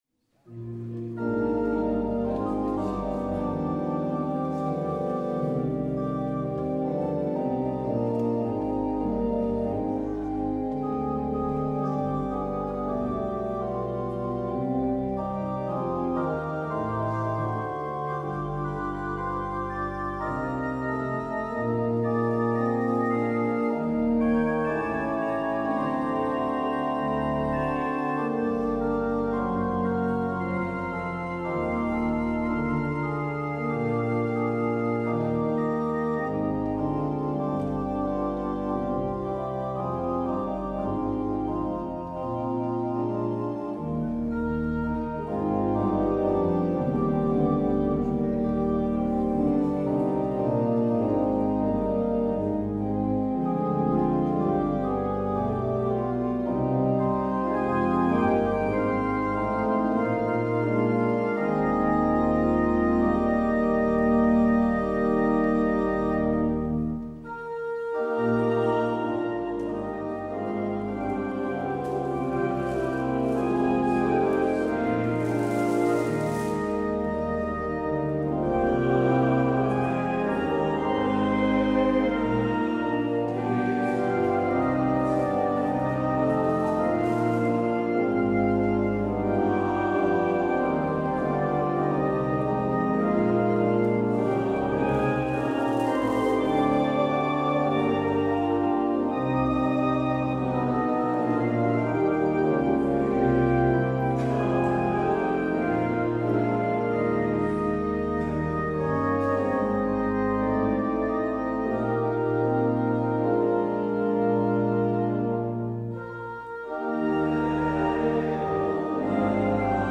 Beluister deze kerkdienst hier: Alle-Dag-Kerk 16 april 2025 Alle-Dag-Kerk https